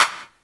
Index of /90_sSampleCDs/Best Service ProSamples vol.15 - Dance Drums [AKAI] 1CD/Partition B/CLAP 45-88